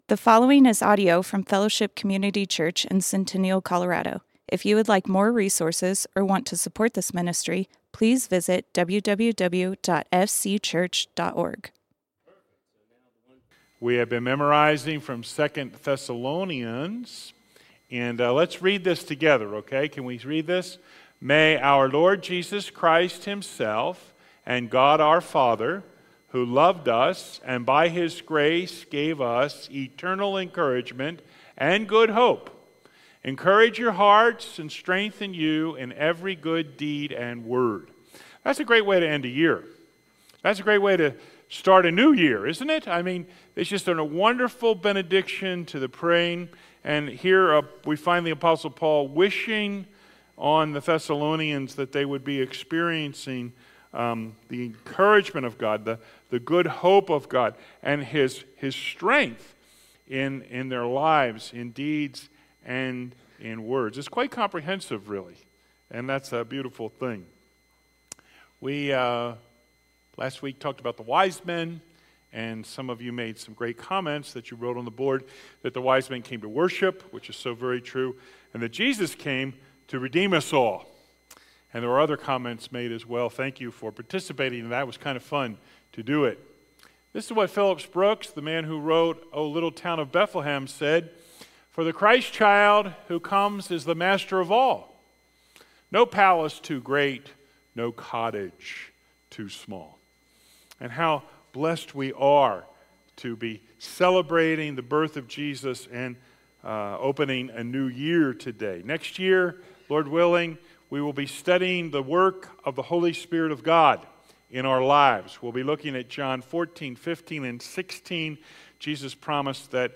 Fellowship Community Church - Sermons Glorious Gospel Play Episode Pause Episode Mute/Unmute Episode Rewind 10 Seconds 1x Fast Forward 30 seconds 00:00 / 34:45 Subscribe Share RSS Feed Share Link Embed